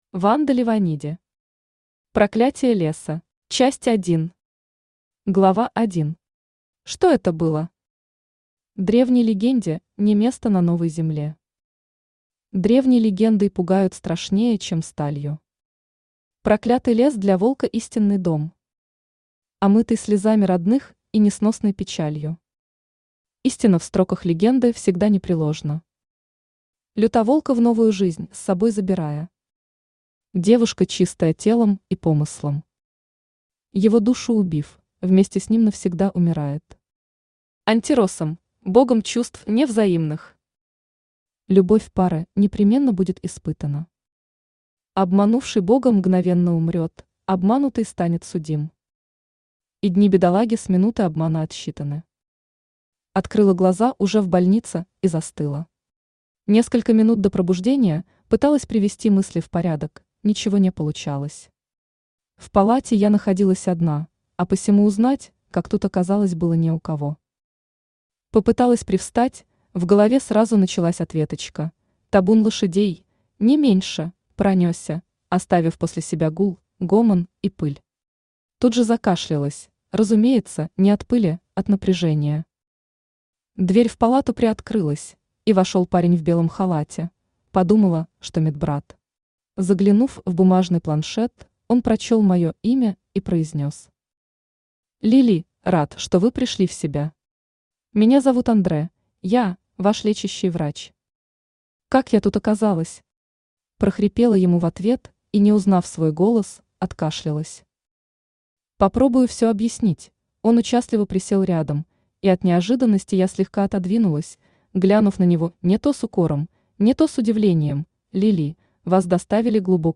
Аудиокнига Проклятие Леса | Библиотека аудиокниг
Aудиокнига Проклятие Леса Автор Ванда Леваниди Читает аудиокнигу Авточтец ЛитРес.